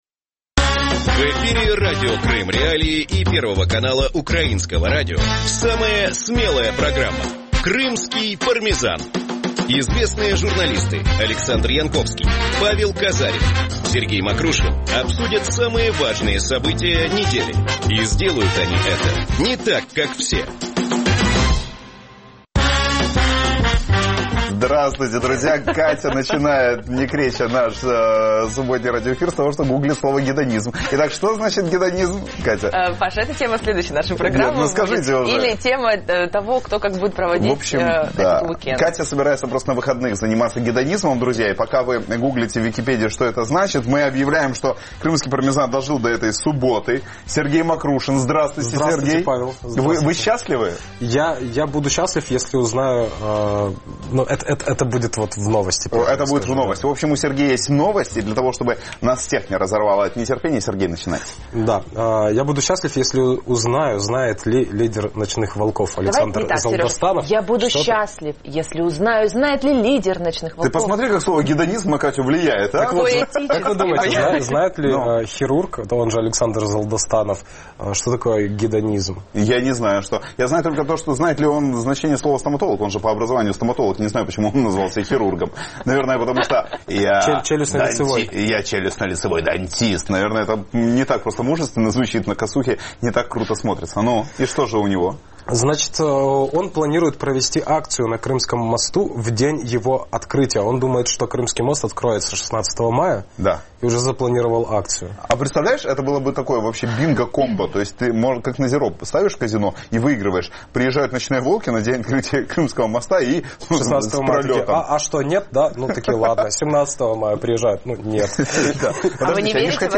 Программу можно слушать в Крыму на Радио Крым.Реалии (105.9 FM), на средних волнах (549 АМ), а также на сайте Крым.Реалии.